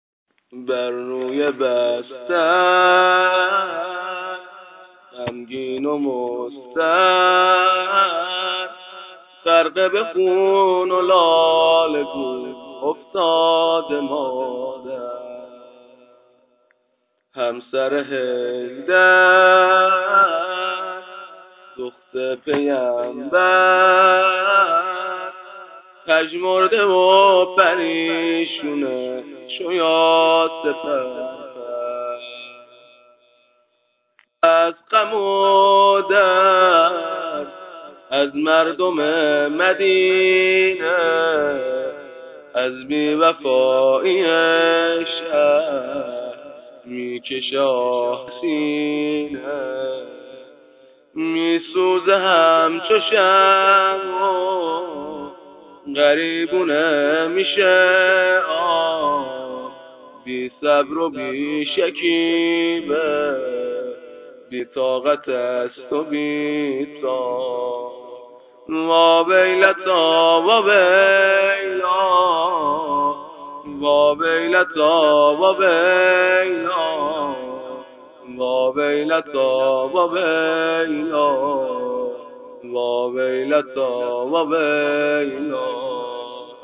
نوحه واحد کند فاطمیه به همراه دانلود سبک